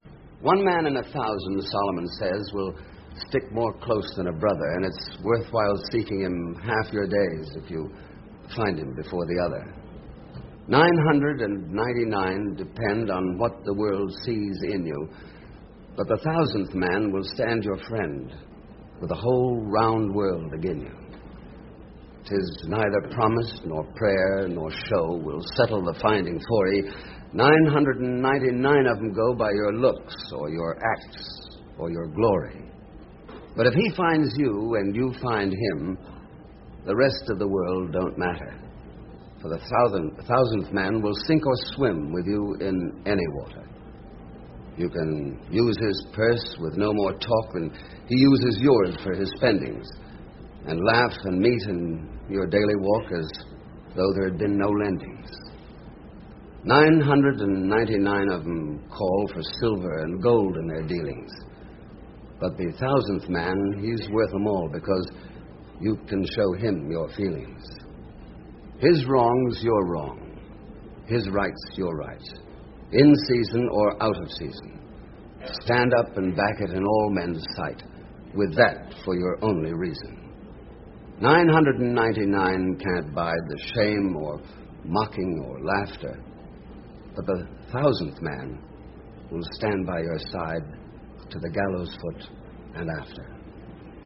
А вот как всё это звучит в оригинале — в одной из программ телешоу Мерва Гриффина, очень популярного в своё время в Америке, стихотворение «The Thousandth Man» по памяти, безо всякой подготовки прочитал известнейший голливудский актёр Дуглас Фэрбенкс-младший (сын Дугласа Фэрбенкса-старшего, крупнейшей кинозвезды немого кино, первого президента Американской академии киноискусства, который вместе с Чарли Чаплином и Мэри Пикфорд основал в 1919 году кинокомпанию «United Artists»).
читает Дуглас Фэрбенкс-младший (скачать)